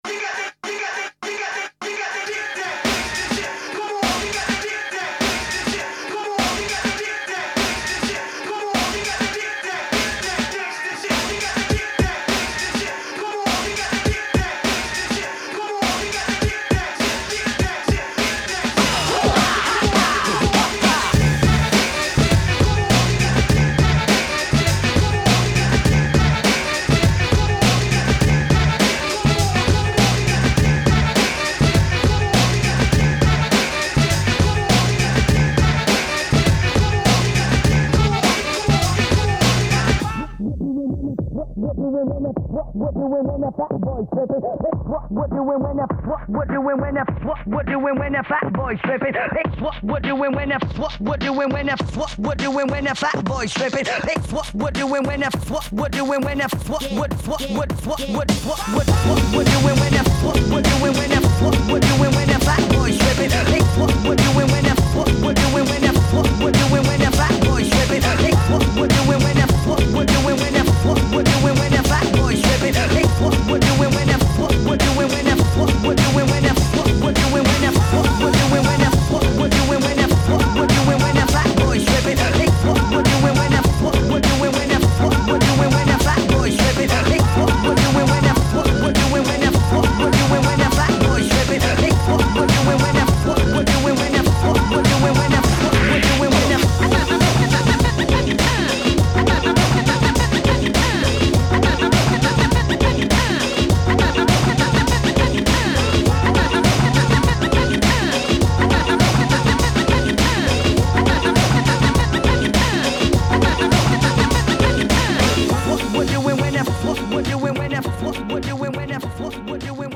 BPM102
Audio QualityMusic Cut